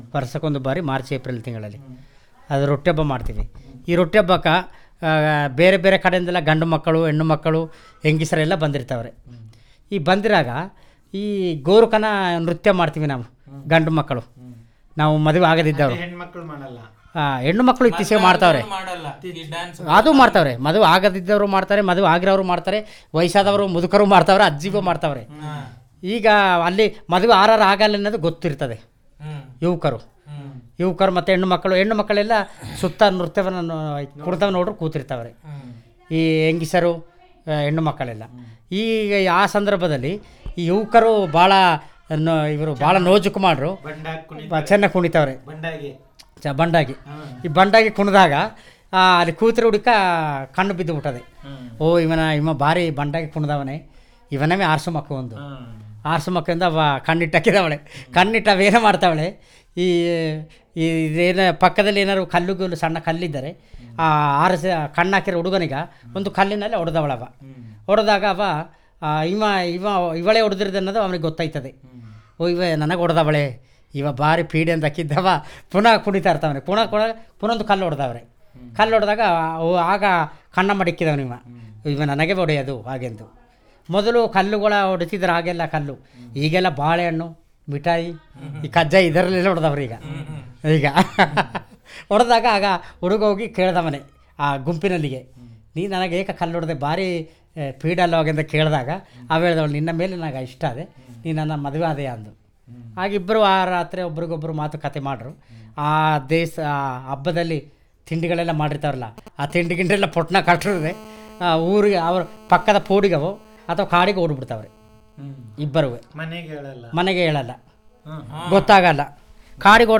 Personal Narrative on about the Marriage